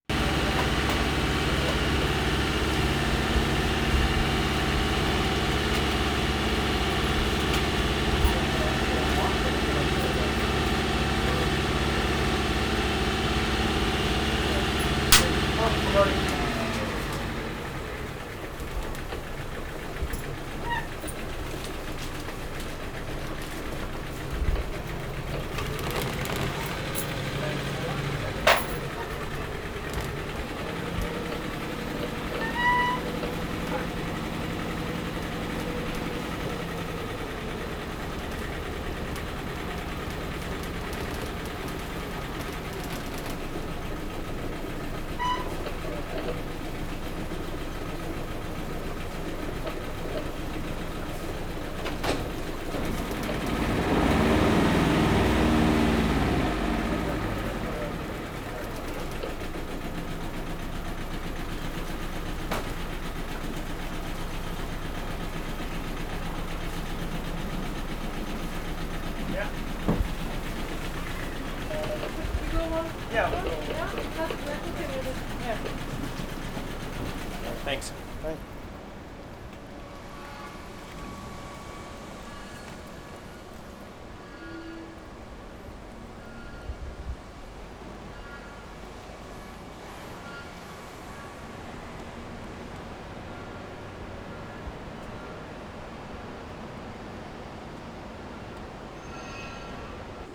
beach west of Maritime Museum 5:51
11. ID, waves, birds, boat traffic, boat horn, motorcycle, dog bark at 1:53, ominous drones from boats, etc.